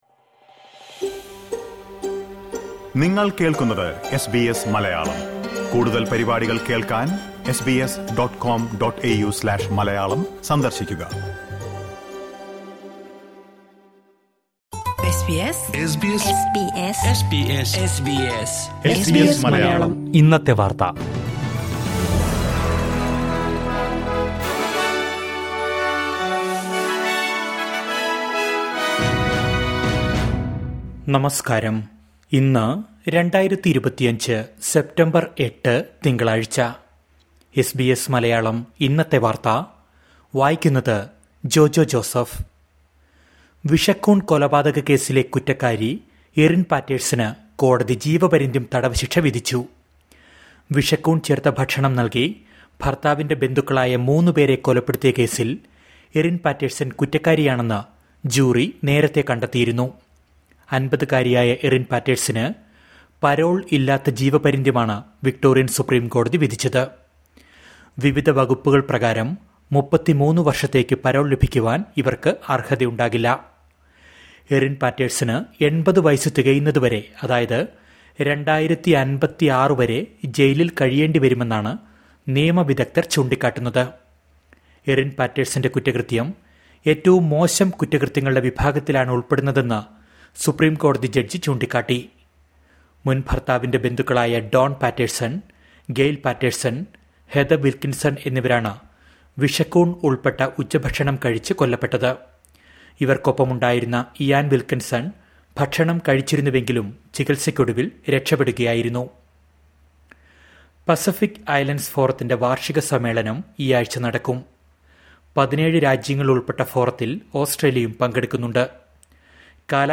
2025 സെപ്റ്റംബർ എട്ടിലെ ഓസ്ട്രേലിയയിലെ ഏറ്റവും പ്രധാന വാർത്തകൾ കേൾക്കാം...